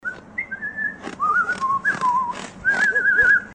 лай собак